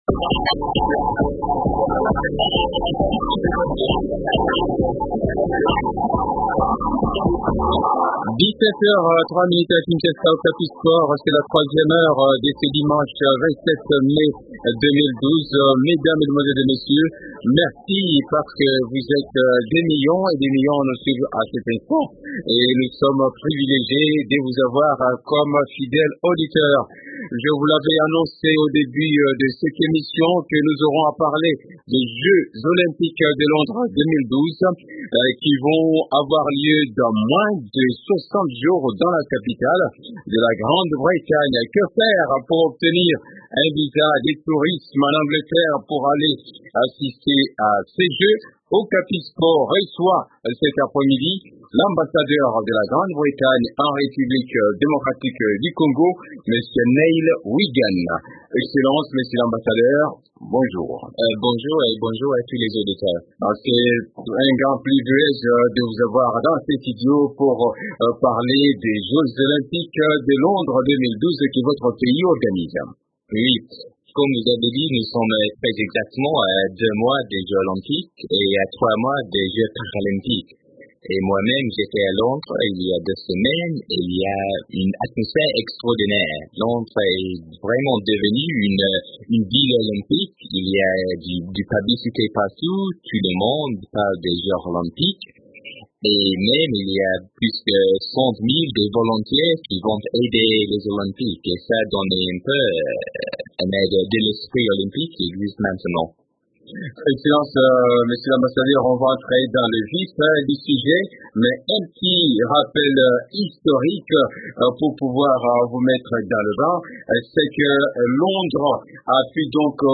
Dans une interview accordée le dimanche 27 mai à Radio Okapi, l’ambassadeur de Grande Bretagne en RDC, Neil Wigan, a dit espérer qu’au moins un athlète congolais remportera une médaille lors des prochains Jeux olympiques organisés à Londres du 27 juillet au 12 août 2012.
Neil Wigan, ambassadeur britannique en RDC, dans le studio de Radio Okapi à Kinshasa Dimanche 27 mai 2012.